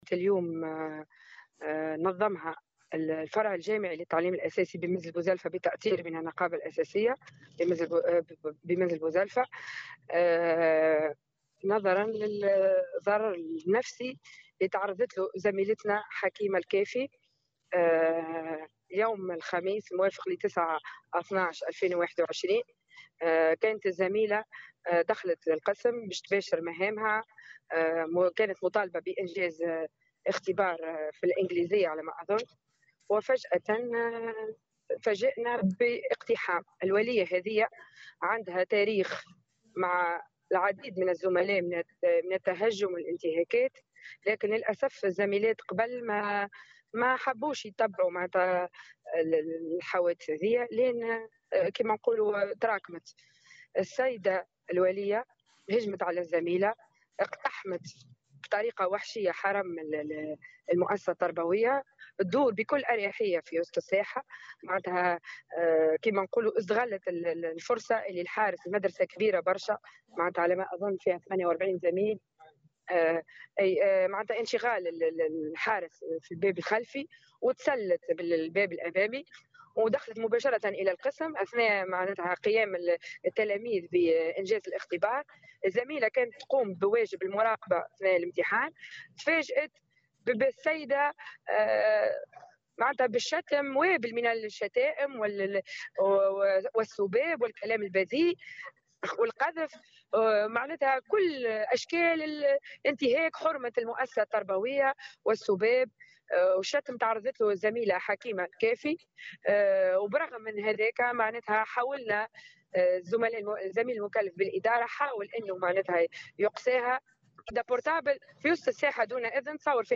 وأضافت في تصريح لمراسلة "الجوهرة أف أم" أنه قد تم التشهير بزميلتهم عبر نشر فيديوهات على مواقع التواصل الاجتماعي من قبل ولية اقتحمت قاعة الدرس بينما كان التلاميذ يجرون اختباراتهم وتوجهت لها بوابل من الشتائم على مرأى ومسمع من تلاميذها.